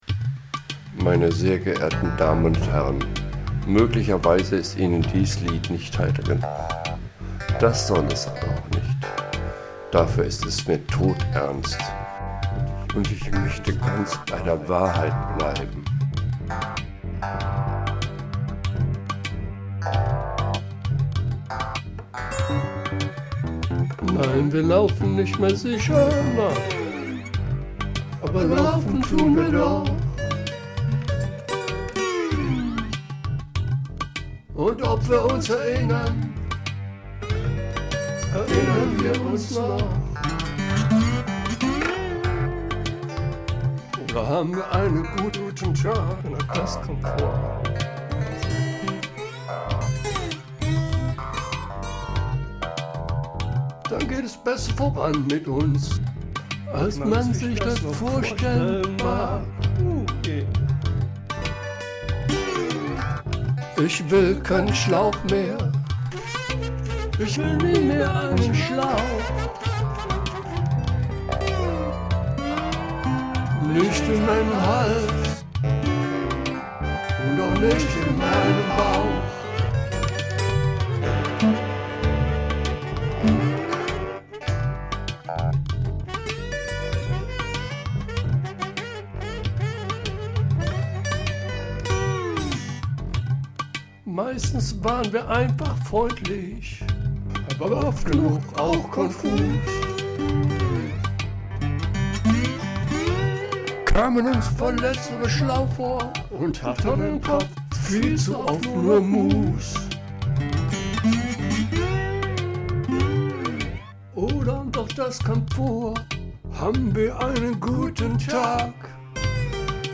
Um meine alleinige Leistungen glaubhafter zu machen, ging ich auch zum massiven Einsatz mehrerer Stimmen über.
Natürlich können Sie sich auch meinen Bonding"Rap" , oder mein etwas bluesiges
Mit  demonstriere ich die sinnlos ungenutzten therapeutischen Möglichkeiten (Manche Stücke sind leider bei aller Aussagekraft recht leise).
Um es ganz klar zu machen: Bis auf Frauenstimmen und teilweise eingebundene Standartrhytmen stammt jeder Ton der Aufnahmen von mir.